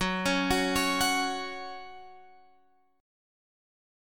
F#5 chord {14 16 16 x 14 14} chord